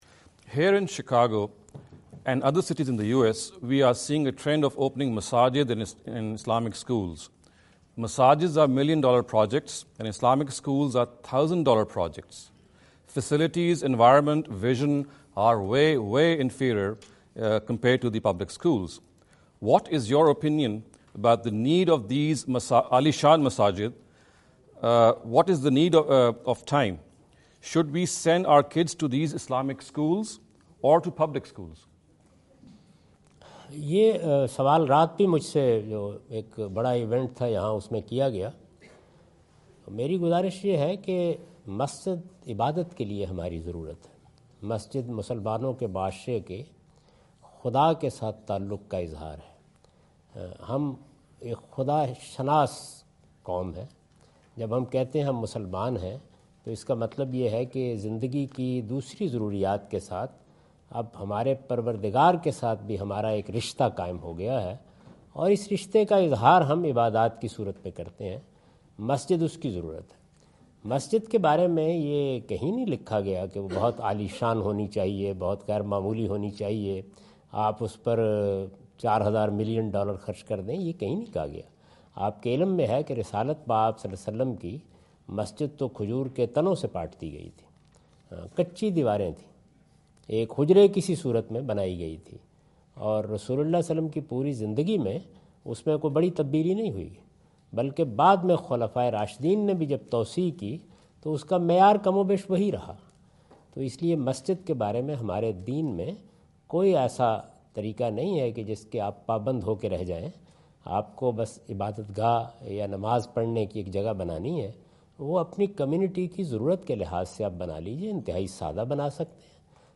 In this video Javed Ahmad Ghamidi answer the question about "mosques in Islamic schools" asked at East-West University Chicago on September 24,2017.
جاوید احمد صاحب غامدی دورہ امریکہ2017 کے دوران ایسٹ ویسٹ یونیورسٹی شکاگو میں " امریکہ میں اسلامی سکولوں میں مساجد کے قیام کی اہمیت" سے متعلق ایک سوال کا جواب دے رہے ہیں۔